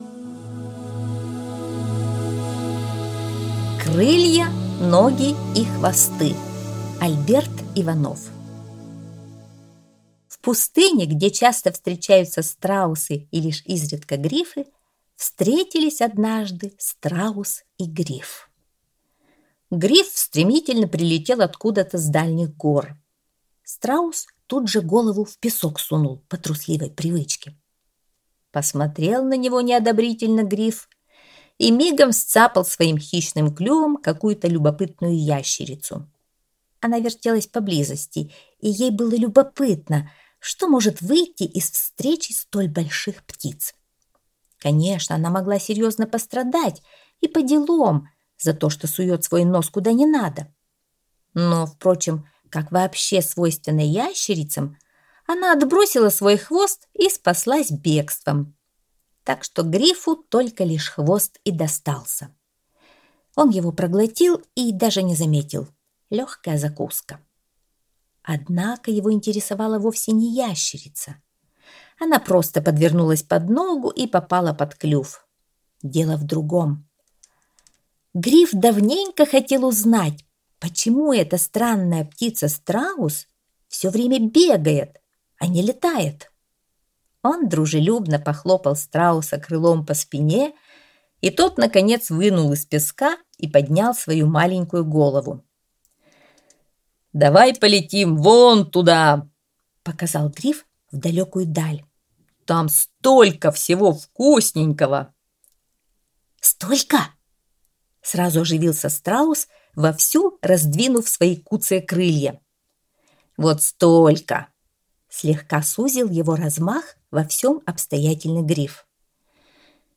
Аудиосказка «Крылья, ноги и хвосты»